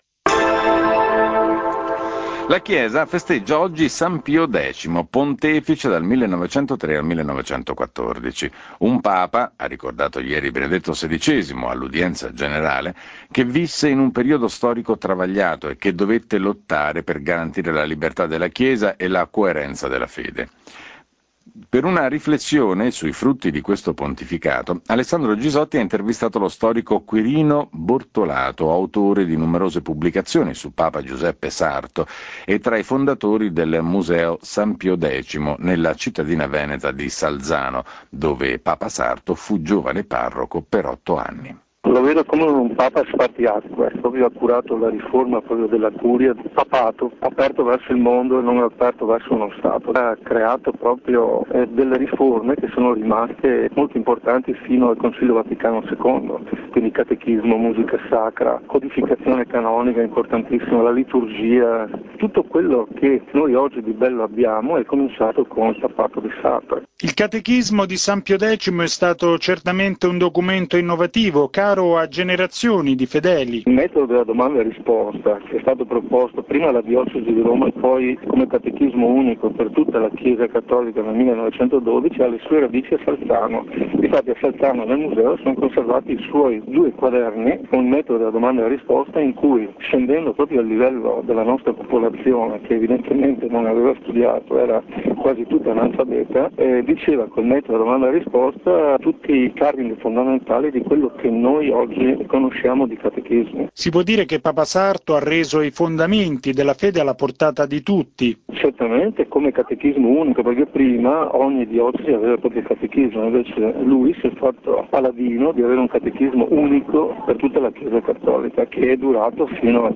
Radiogiornale di Radio Vaticana 21 agosto 2008 - Intervista